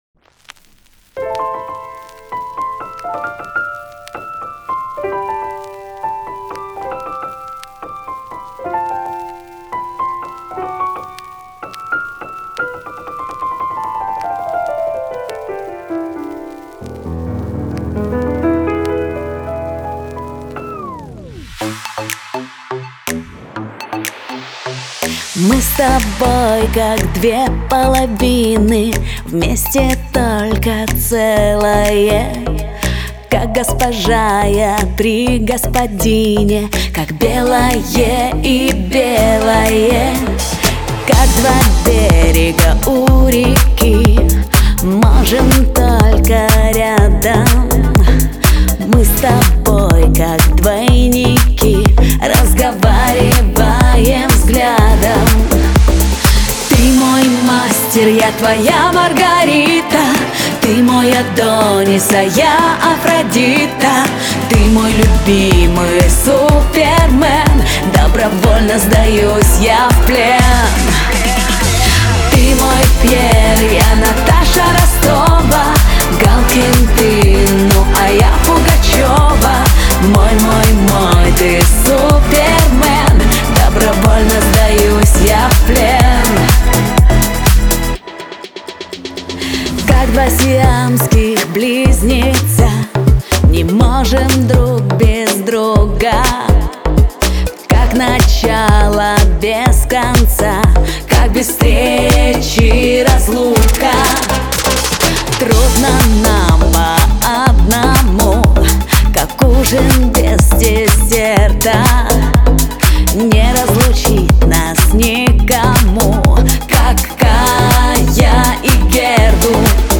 это проникновенная песня в жанре поп